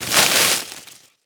vines.wav